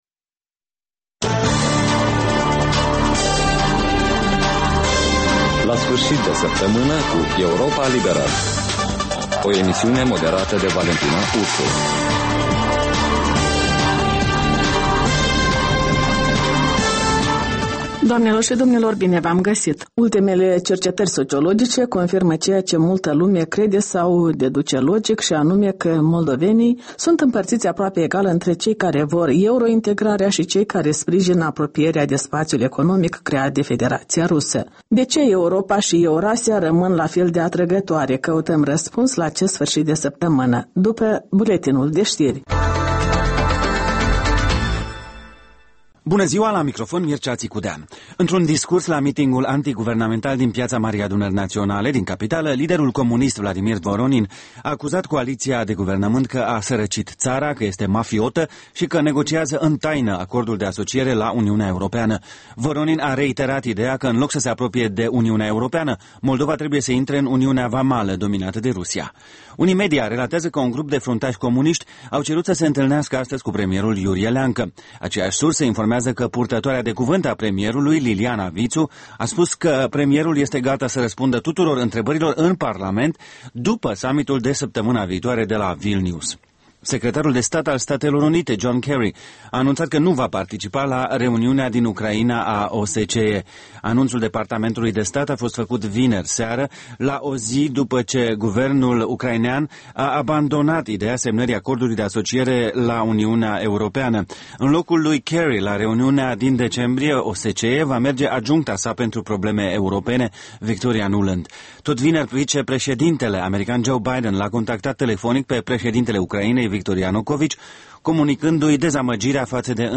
reportaje, interviuri, voci din ţară despre una din temele de actualitate ale săptămînii. In fiecare sîmbătă, un invitat al Europei Libere semneaza „Jurnalul săptămînal”.